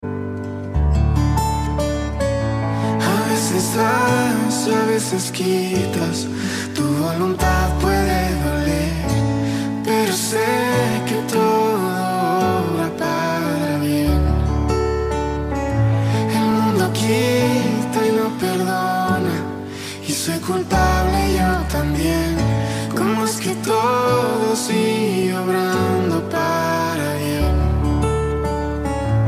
Frases y adoración ♥✨🇵🇪